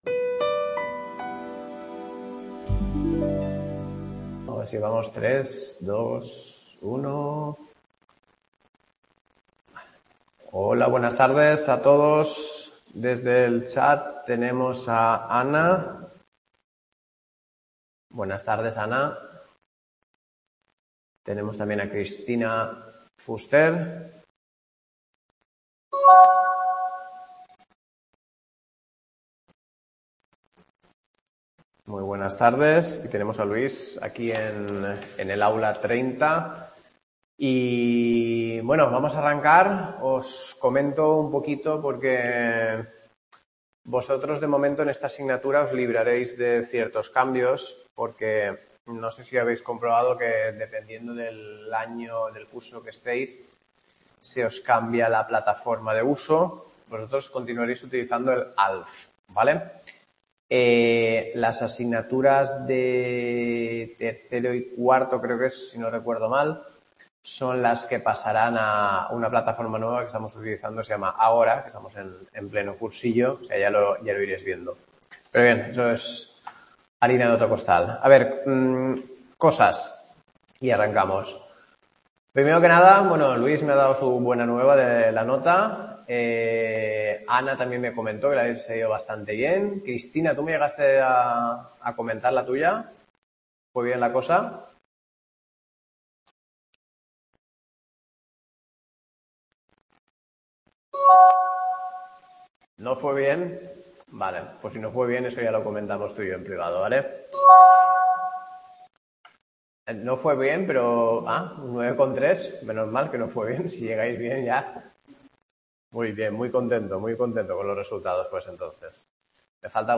CLASE 1 LITERATURA DEL XVIII Y XIX | Repositorio Digital